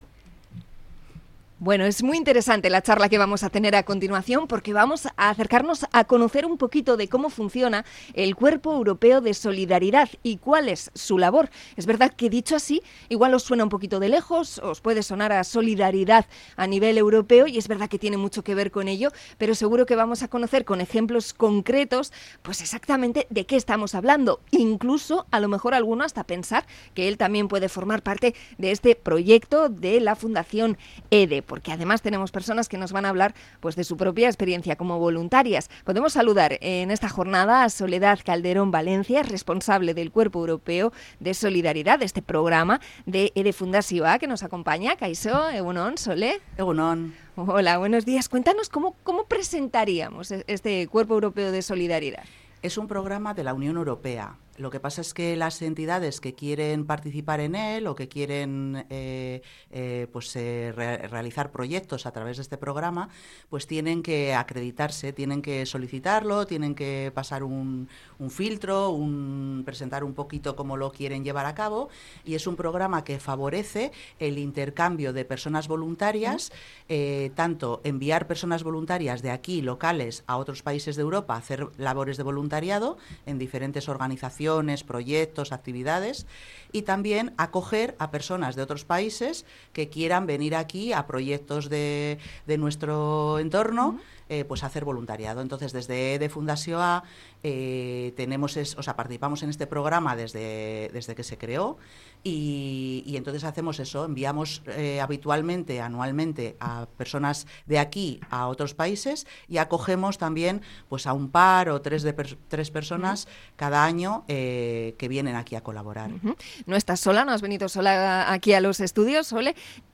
Entrevista sobre el Cuerpo Europeo de Solidaridad
Dos voluntarias nos cuentan su experiencia en Bilbao